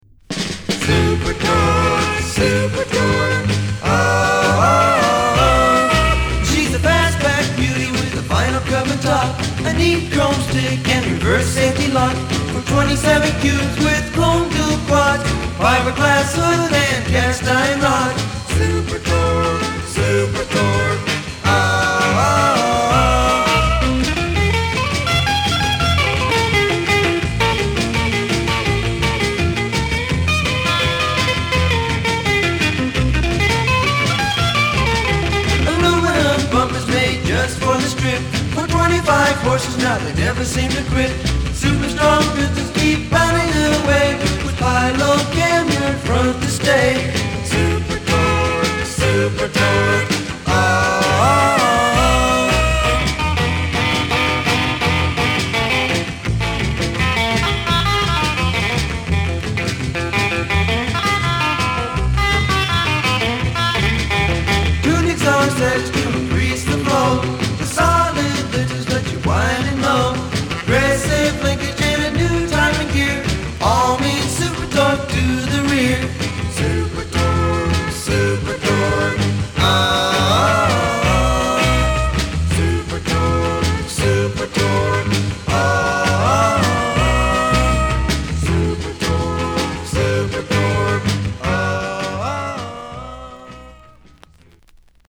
ピュアでストレート、余計な装飾のないサウンド・プロダクションが魅力的だ。